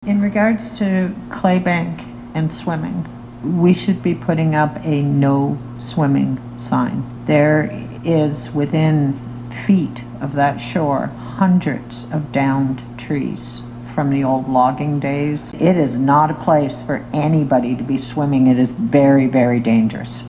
Deputy Mayor Lori Hoddinott says there are historical reasons the site is not safe for swimming.